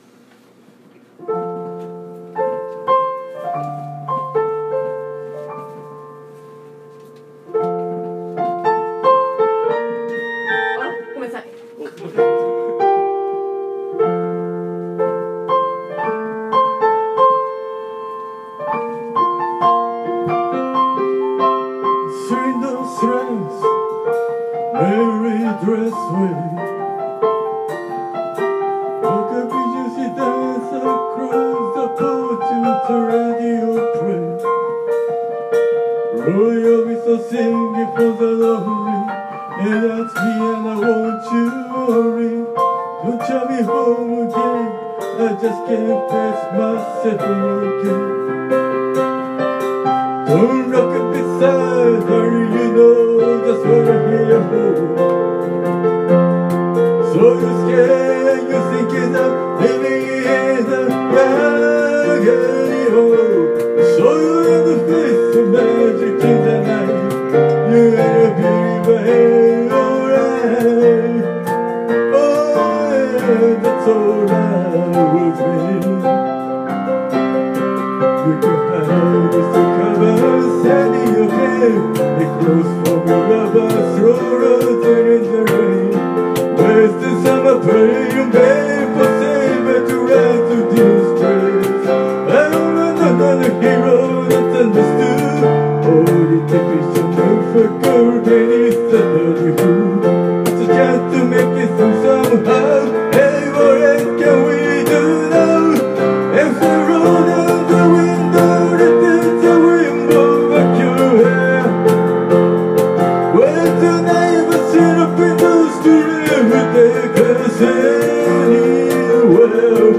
Acoustic Night Vol. 6 TURN TABLE
piano